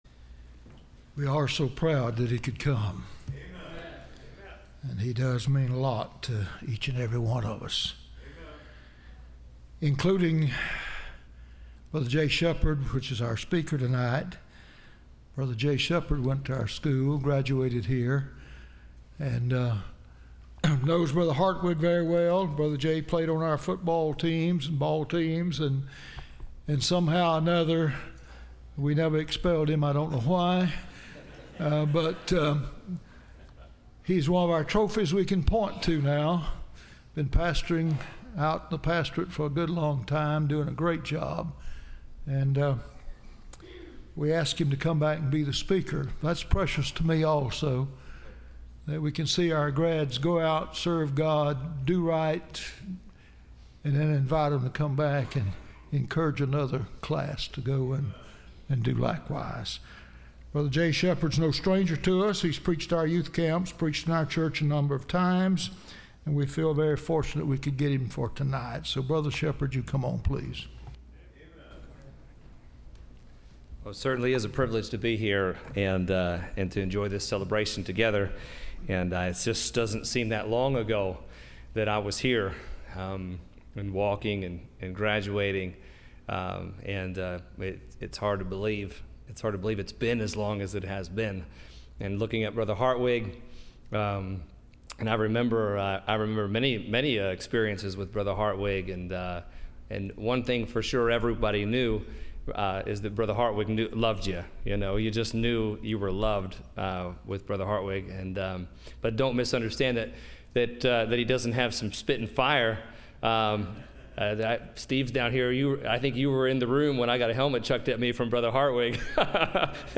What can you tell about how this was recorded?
Service Type: Wednesday